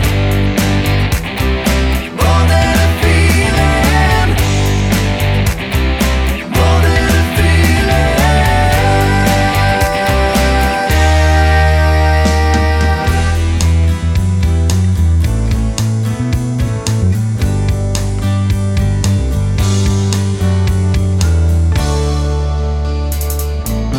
Live Rock 3:47 Buy £1.50